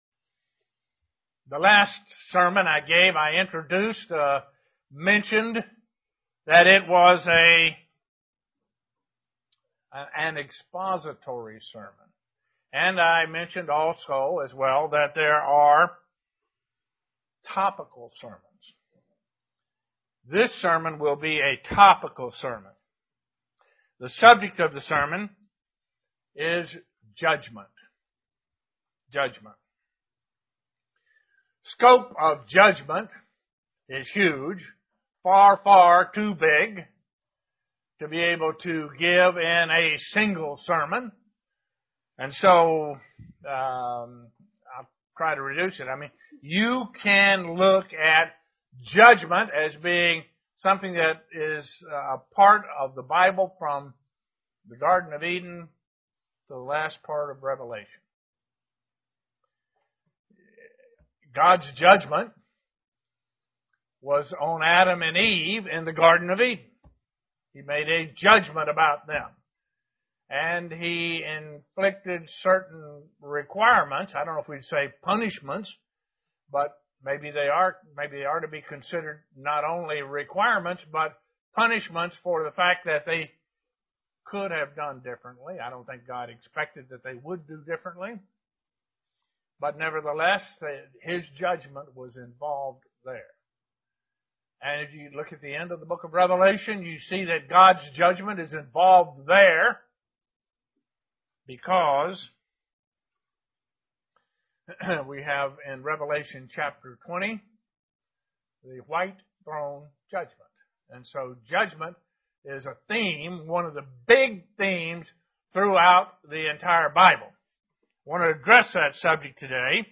Print Judgment at the personal level UCG Sermon Studying the bible?